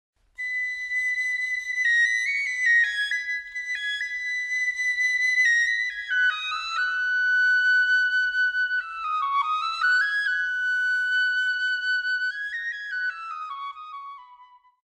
picolo
Semella unha frauta traveseira de dimensións reducidas, e como podedes escoitar soa extremadamente agudo. Resulta difícil quitarse esta melodía tan pegañenta da cabeza, verdade?
PICCOLO_SOUND.mp3